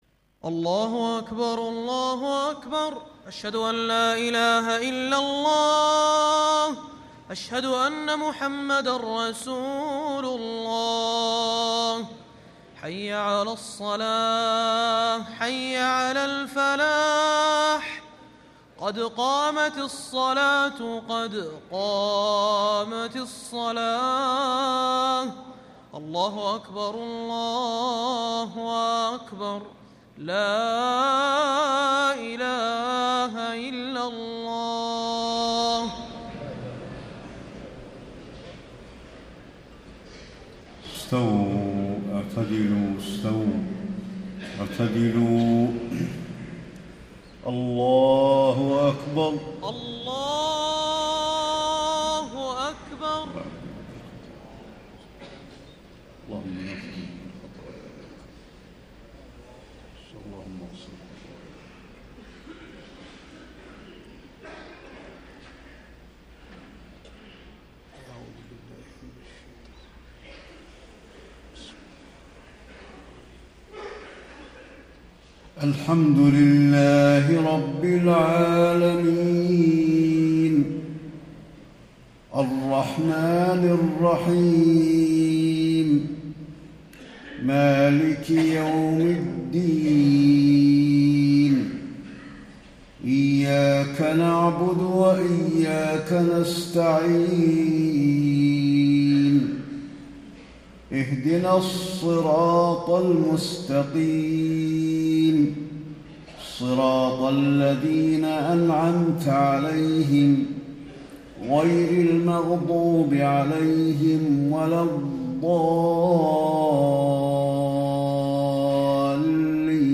صلاة العشاء 6 - 1 - 1435هـ آخر سورتي الحشر و المنافقون > 1435 🕌 > الفروض - تلاوات الحرمين